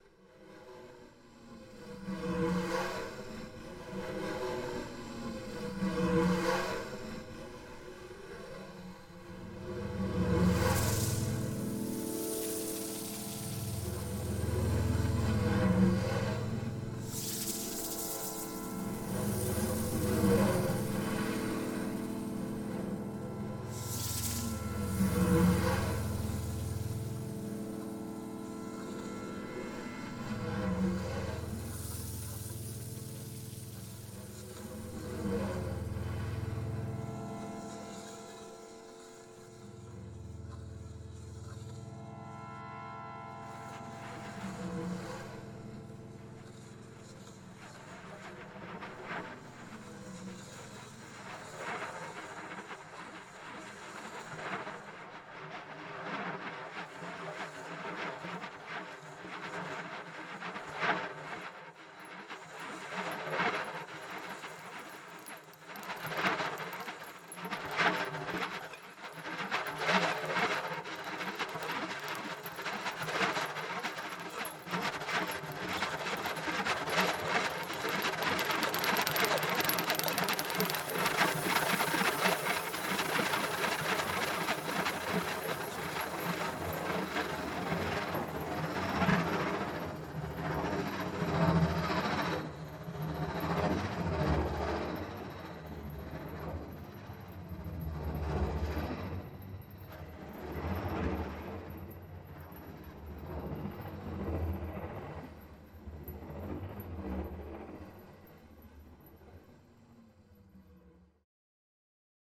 Genre : spectacle danse / musique électroacoustique